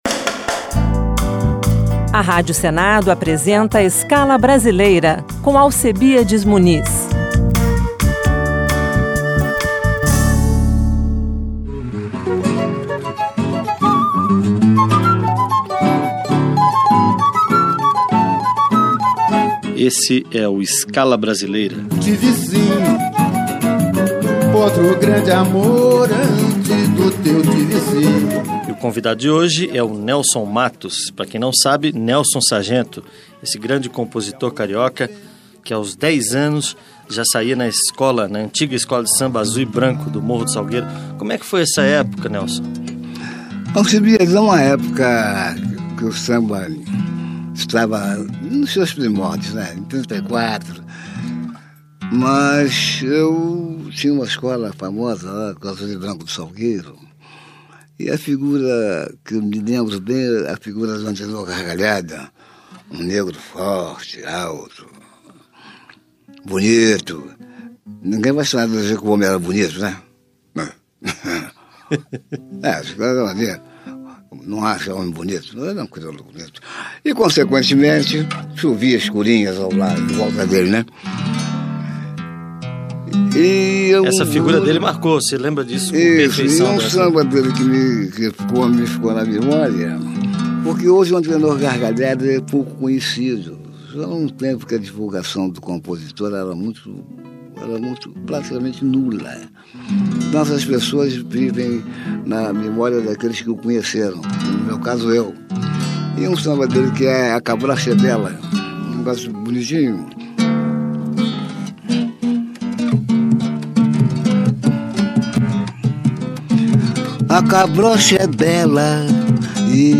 Nesta semana o programa Escala Brasileira reprisa a entrevista feita em 2002 com o cantor e compositor Nelson Sargento, que morreu em 27/05/2021, aos 96 anos, em decorrência da Covid-19.
Nelson Sargento contou um pouco de sua trajetória de vida e artística e ainda premiou os ouvintes com performances exclusivas de suas músicas no violão e voz.
Samba